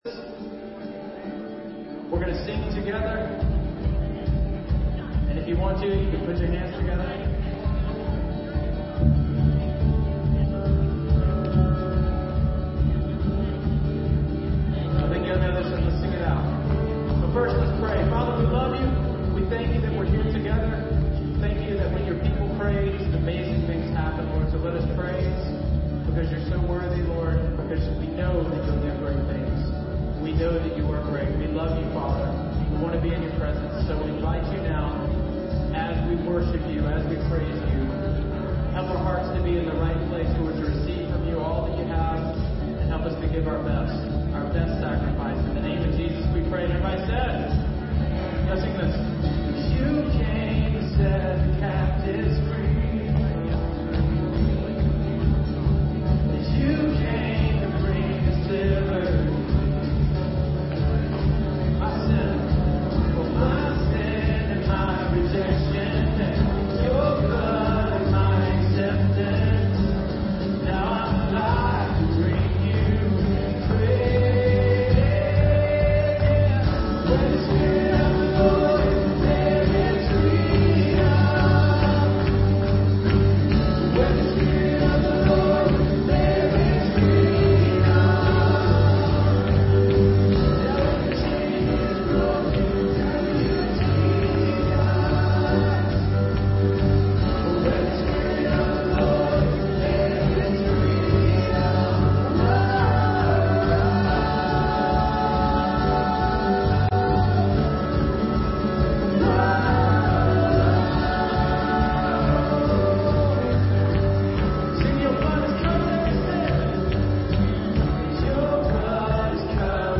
Live Teaching